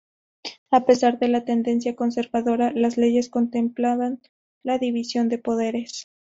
/konseɾbaˈdoɾa/